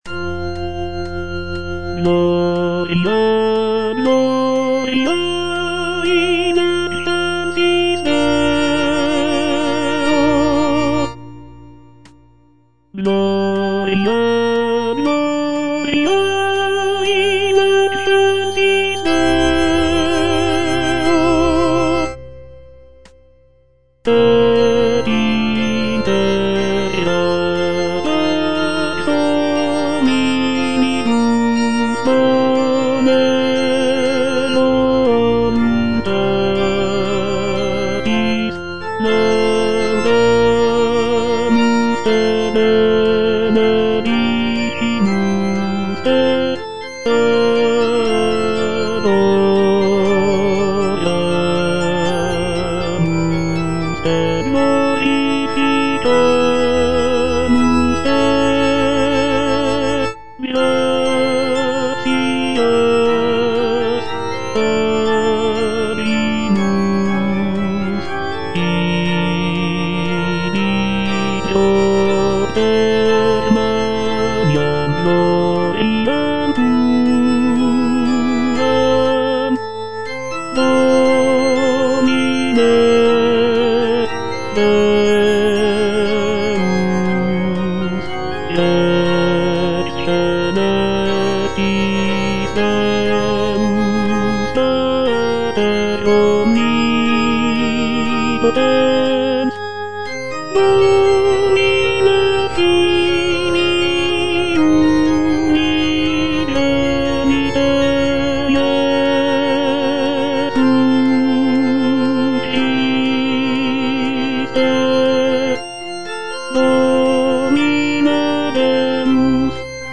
Tenor (Voice with metronome) Ads stop
choral work